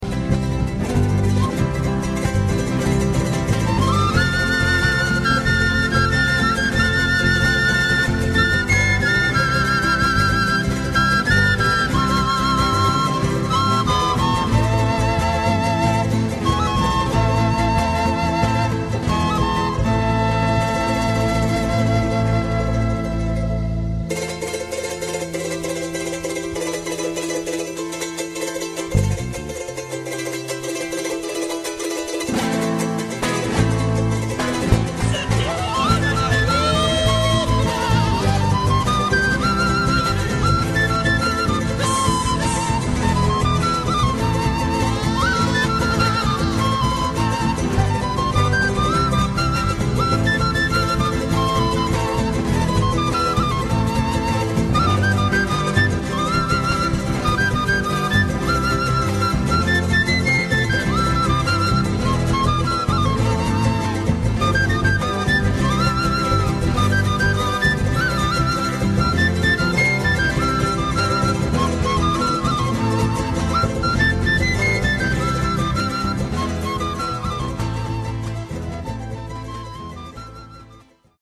Having mentioned this song so often, I did a little research and found that it is a Peruvian song composed in 1913 for a play of the same title.
Group Number One always introduces the song by saying that it is a traditional song from the Andes.